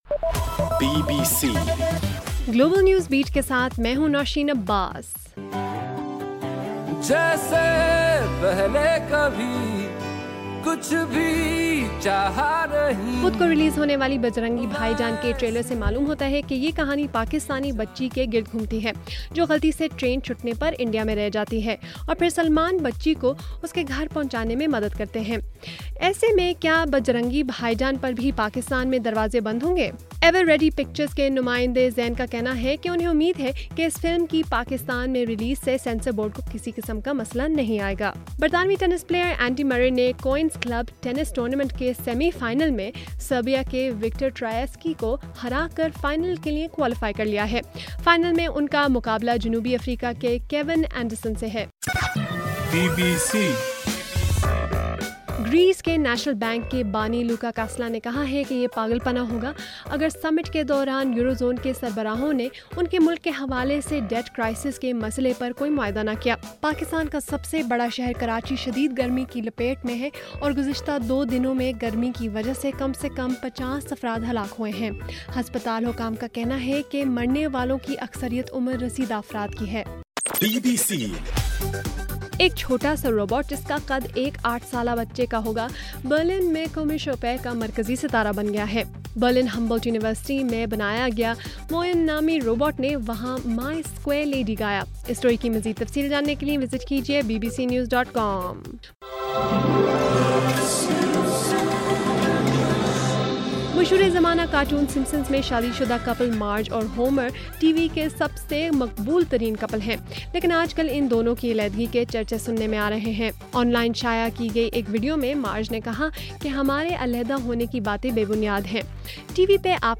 جون 22: صبح1 بجے کا گلوبل نیوز بیٹ بُلیٹن